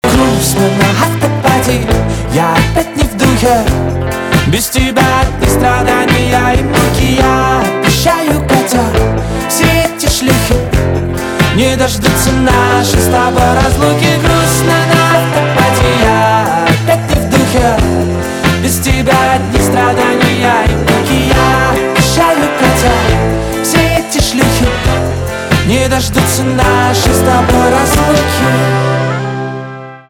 поп
грустные , гитара , басы , барабаны